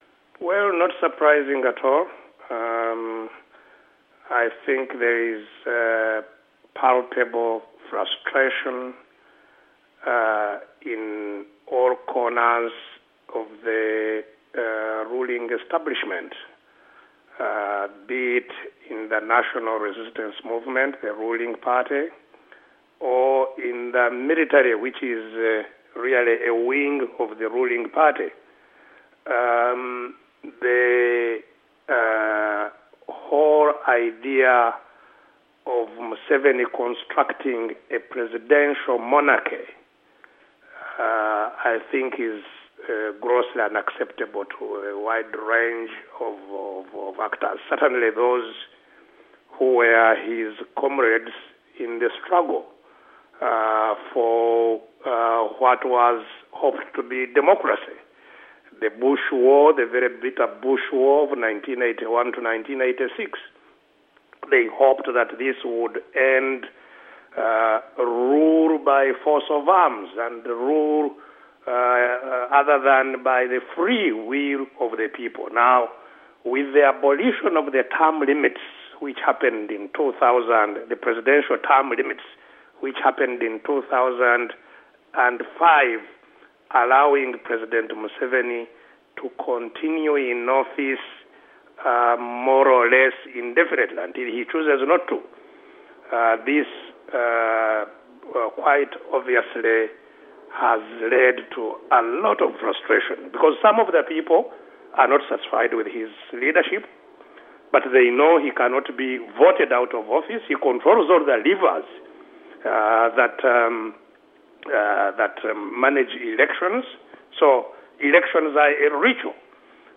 interview with Dr. Kizza Besigye, former FDC leader